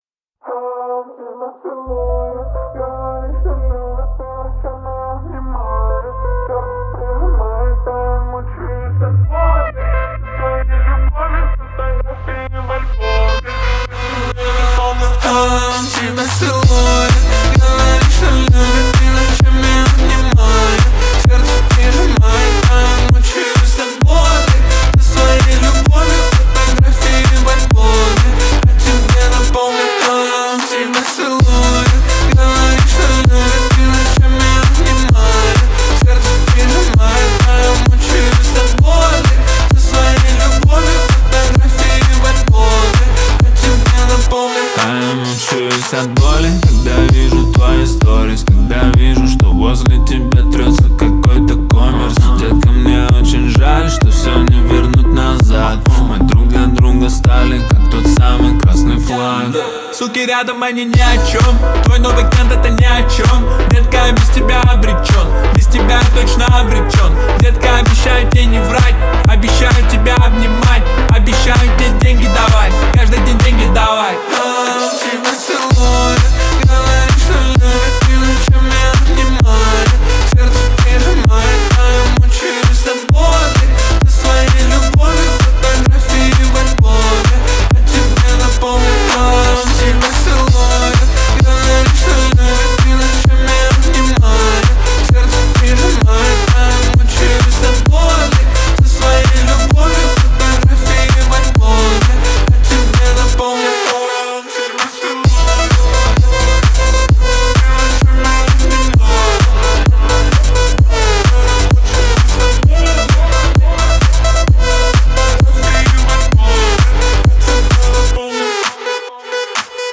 Лаунж